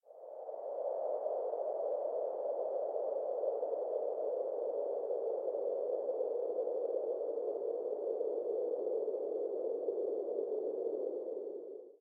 Download Breeze sound effect for free.
Breeze